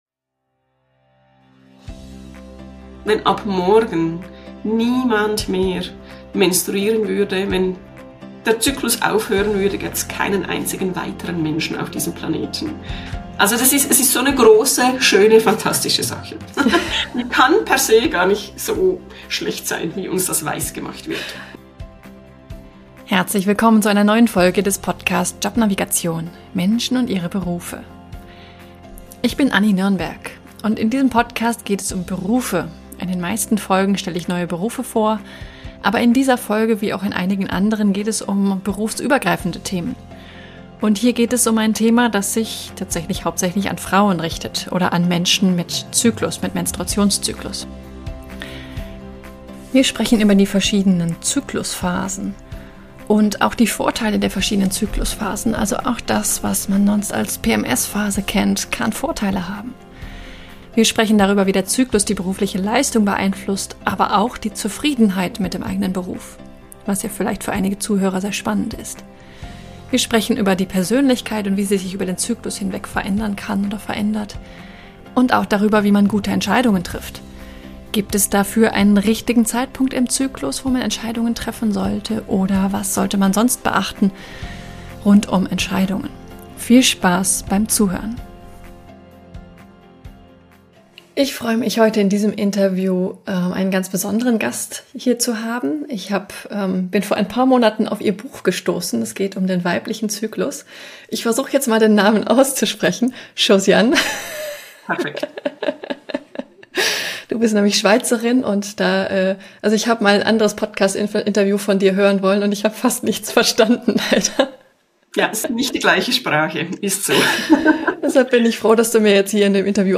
In dieser Folge spreche ich mit einer Expertin über den weiblichen Zyklus und wie er unser Berufsleben beeinflusst – nicht nur für Frauen, sondern auch für Männer spannend!